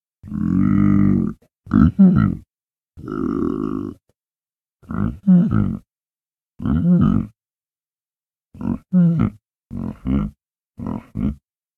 pig_grunt_long.ogg